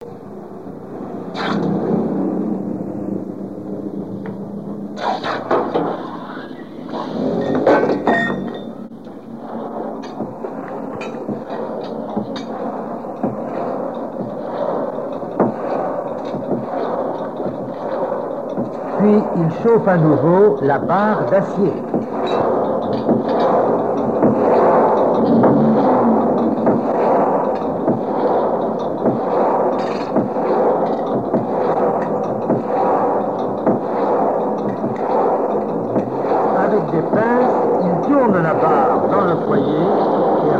Reportage Folklore vivant
forgeron, forge